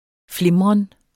Udtale [ ˈflemʁən ]